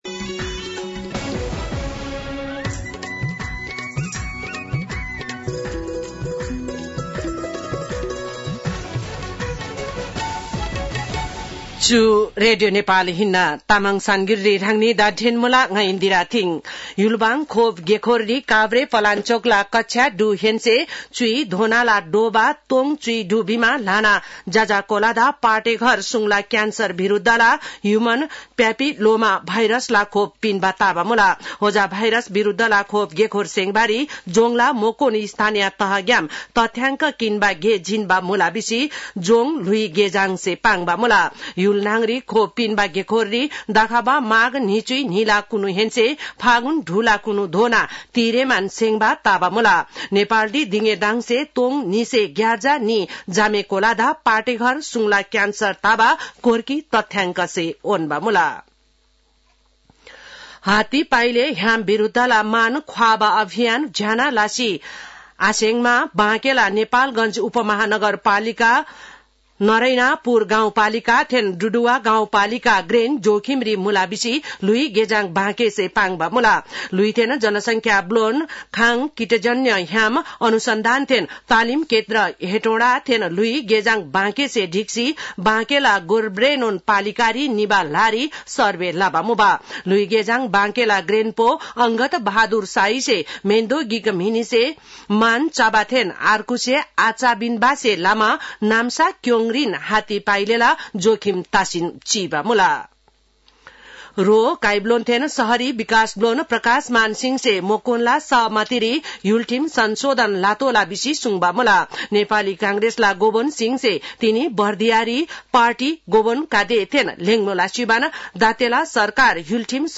तामाङ भाषाको समाचार : २१ पुष , २०८१